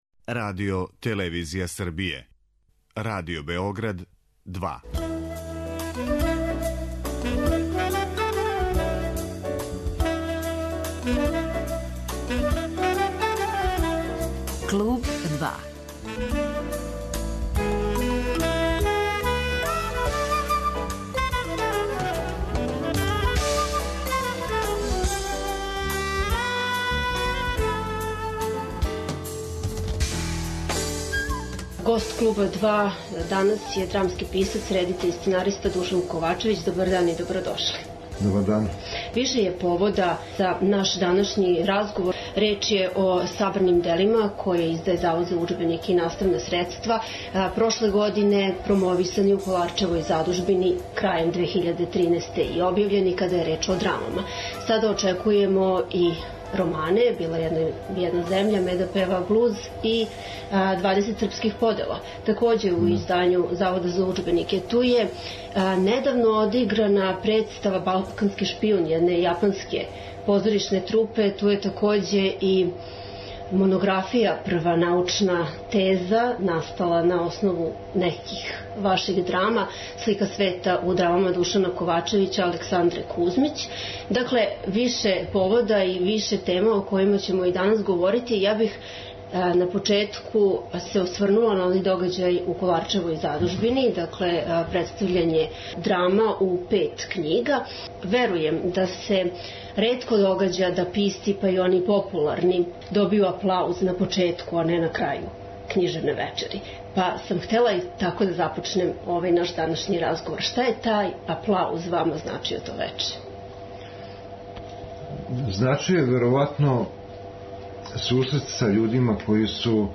Гост емисије је Душан Ковачевић